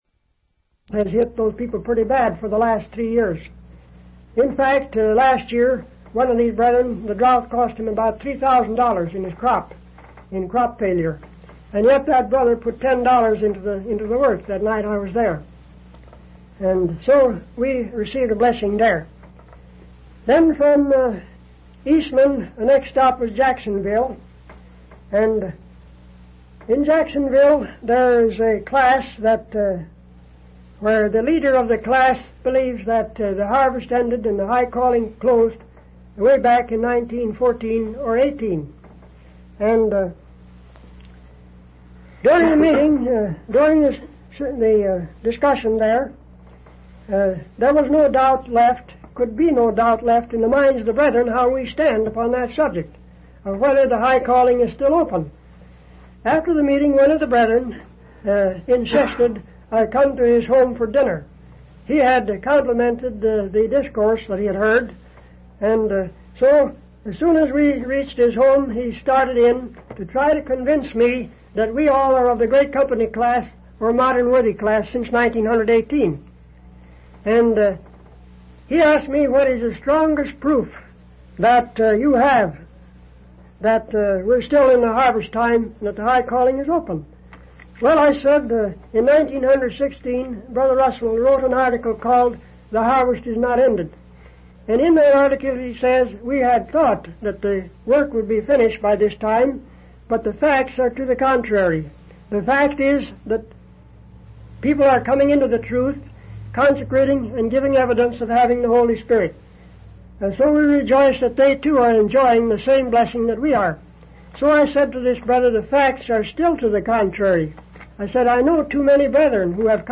From Type: "Discourse"
(starts in progress)